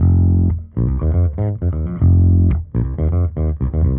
Index of /musicradar/dusty-funk-samples/Bass/120bpm
DF_JaBass_120-F.wav